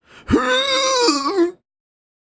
get-sick.wav